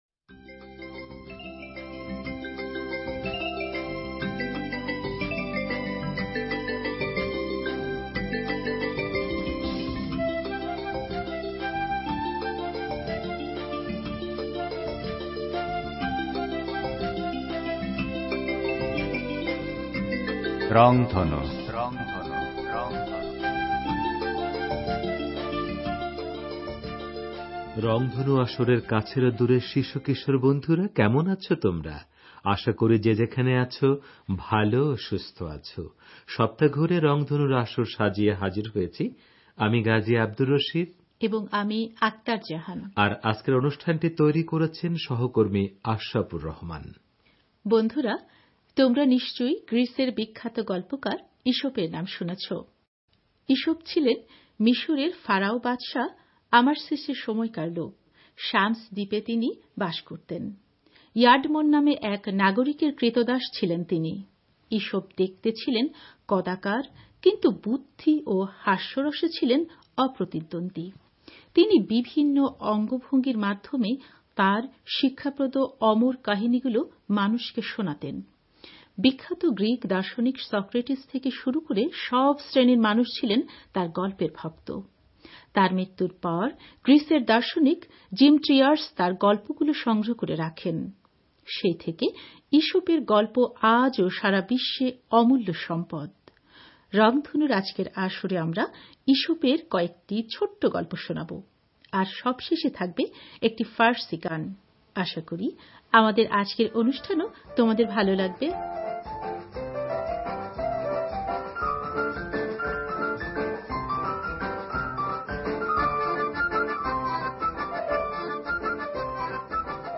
রংধনু আসরের এই পর্বে ঈশপের কয়েকটি ছোট গল্প প্রচার করা হয়েছে। আর সবশেষে রয়েছে একটি ফার্সি গান।